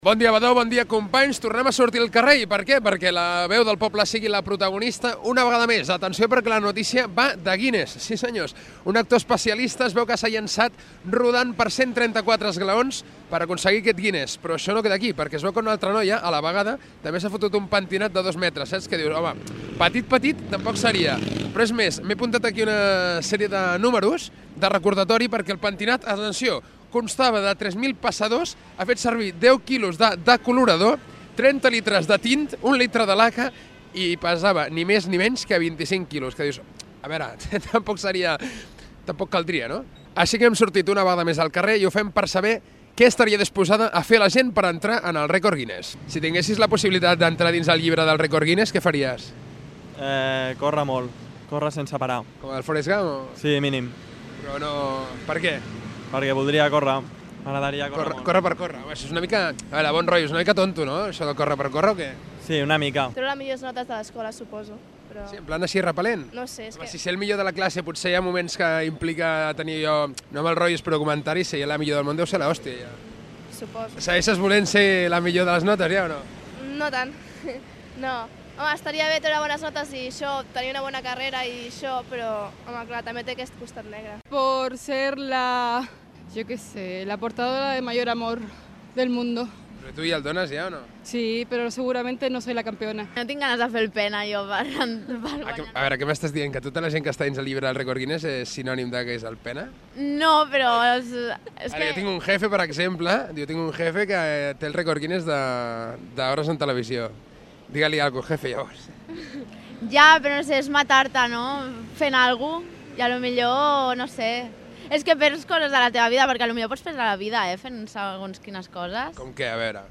Enquesta als vianants sobre el que farien per sortir al llibre "Guinness World Records"